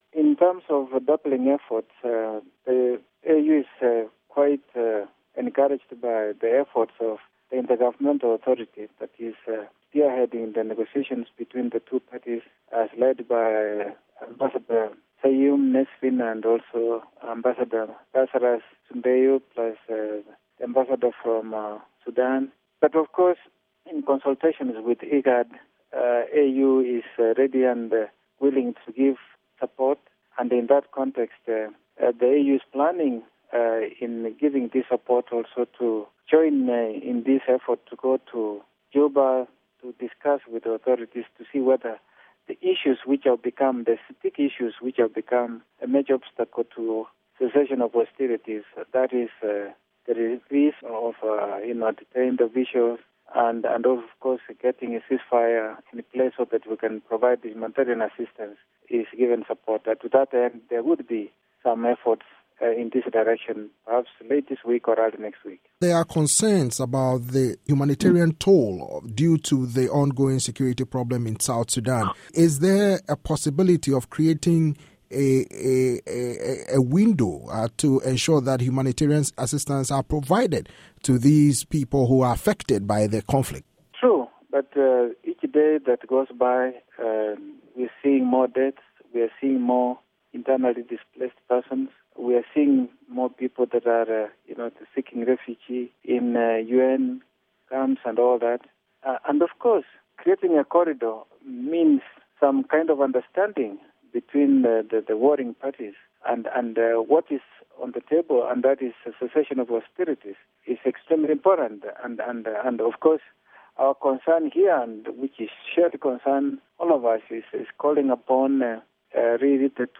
interview with Erastus Mwencha, AU deputy chairman